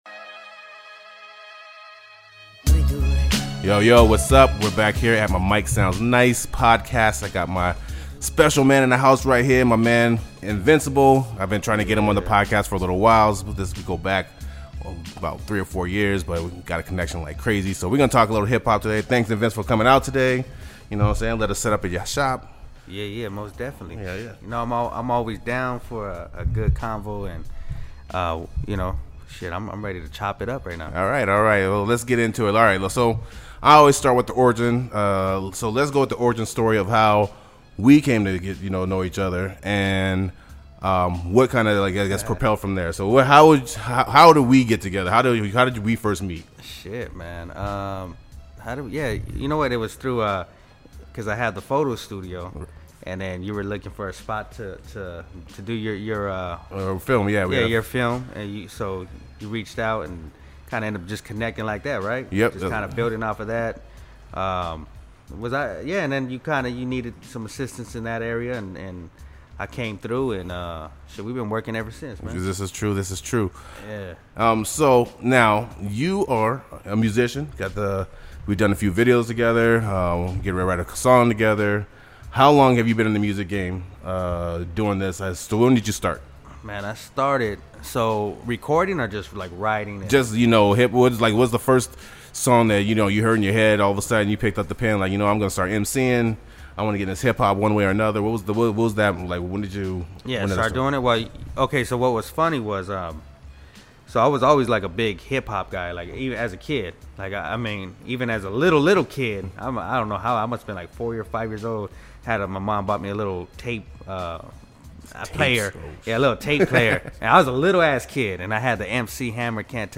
Hip Hop Recording Artist